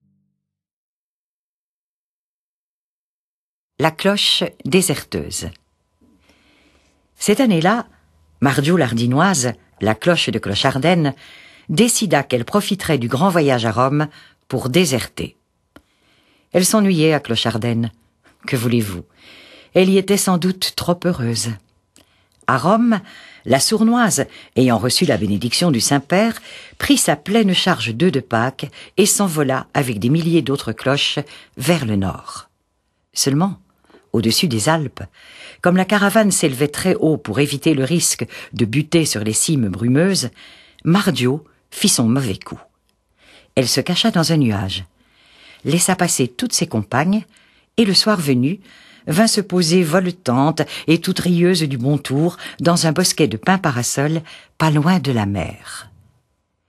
Durée de la lecture intégrale : 1h29.